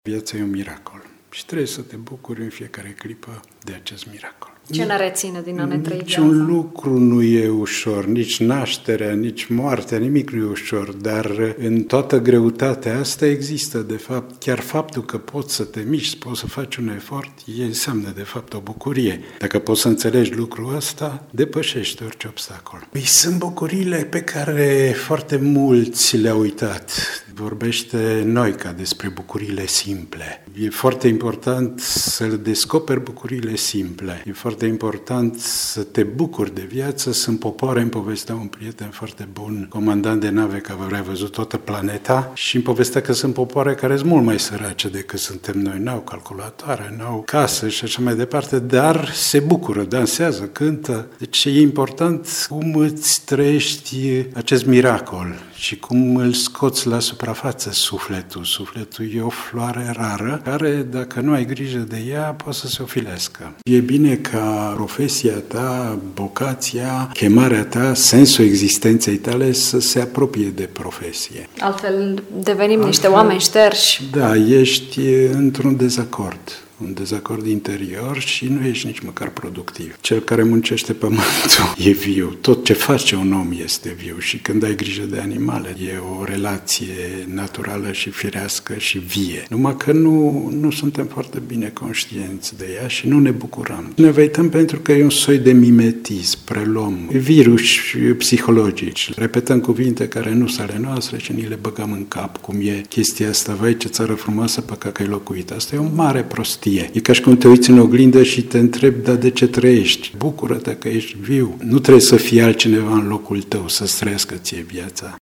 pictor